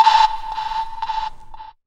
Index of /90_sSampleCDs/Techno_Trance_Essentials/CHOIR
64_19_voicesyn-A.wav